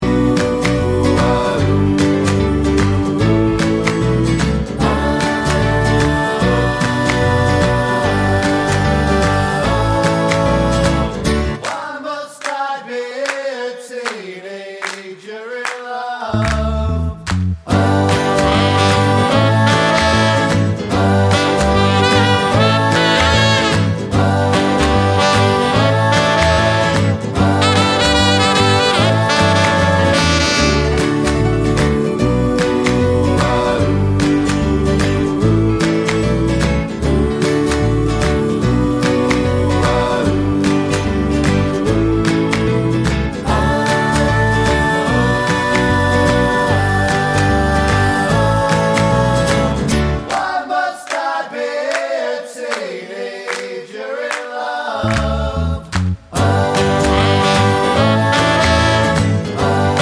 Minus Drums Rock 'n' Roll 3:04 Buy £1.50